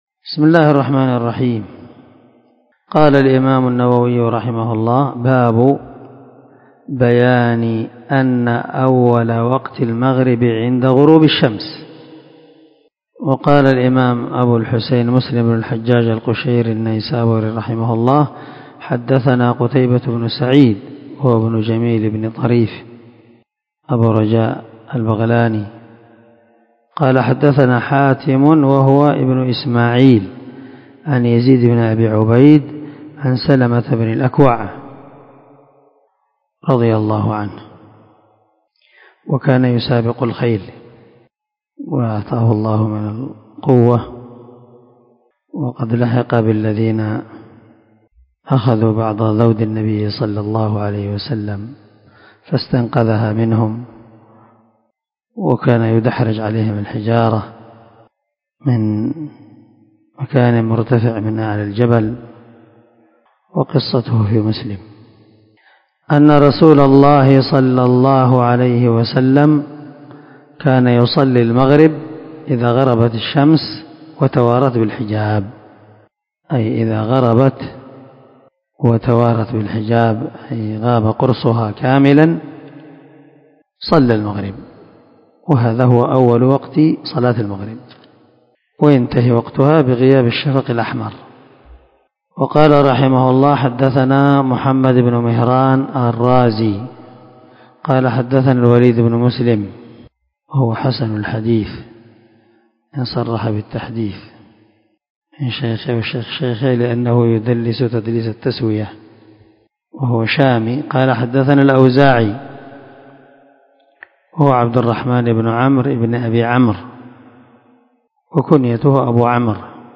403الدرس 75من شرح كتاب المساجد ومواضع الصلاة حديث رقم ( 636 - 637 ) من صحيح مسلم